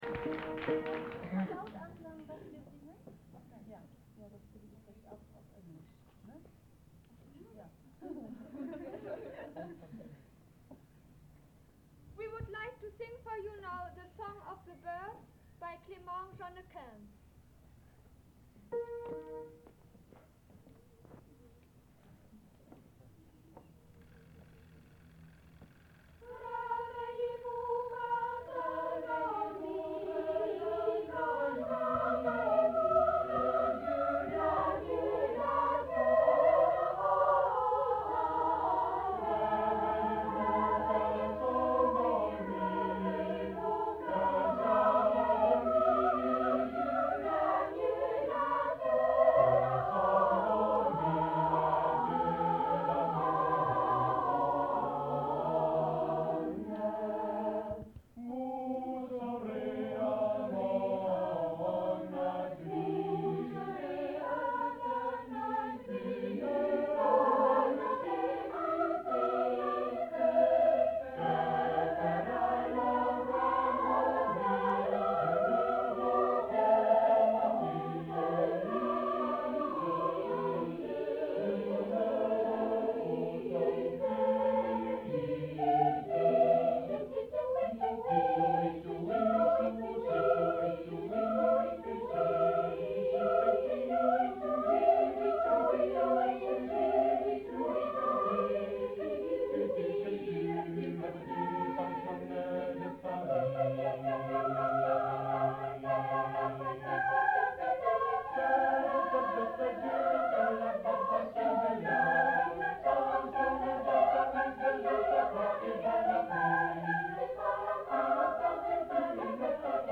Choral Intro Dedication